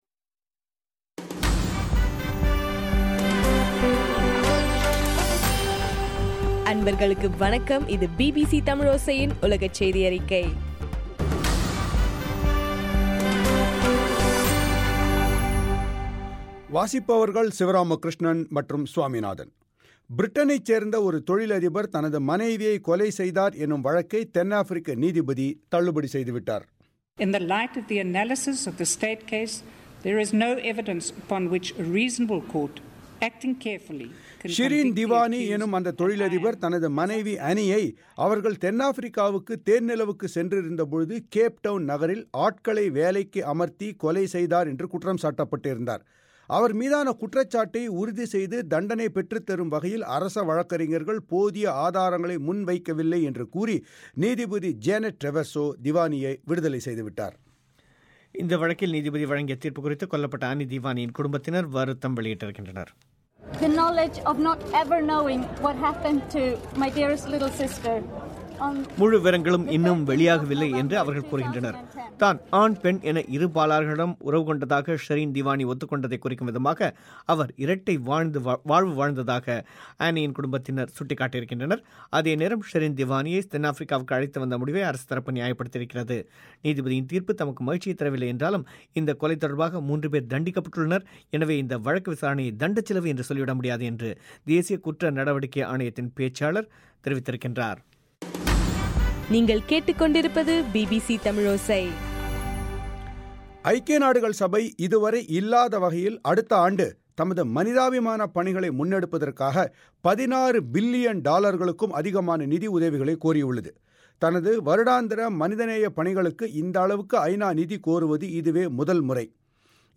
டிசம்பர் 8 2014 பிபிசி தமிழோசையின் உலகச் செய்திகள்